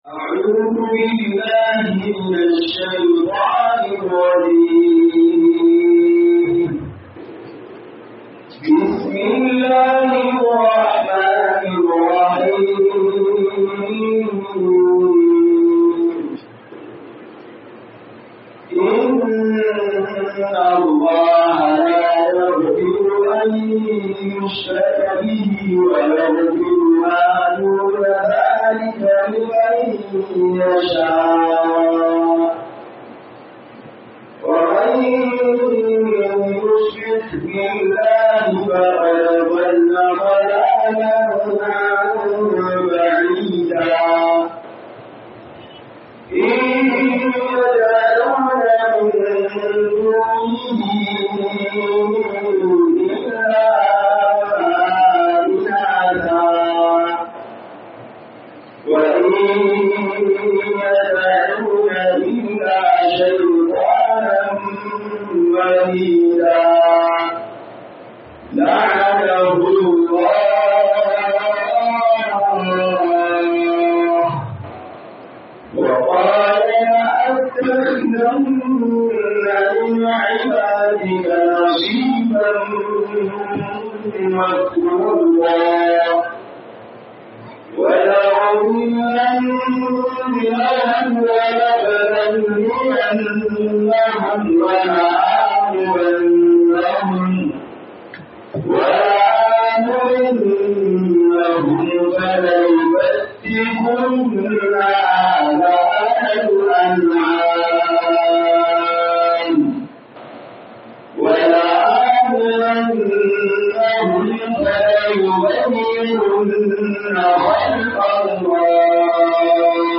MUHADARA A NIAMEY 05